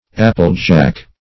Search Result for " apple-jack" : The Collaborative International Dictionary of English v.0.48: applejack \applejack\, Apple-jack \Ap"ple-jack`\, n. Apple brandy; a brandy distilled from cider.